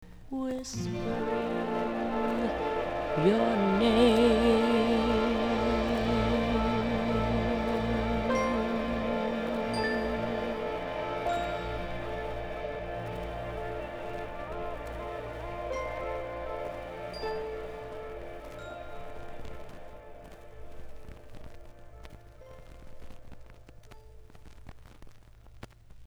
1) LP in average condition:  before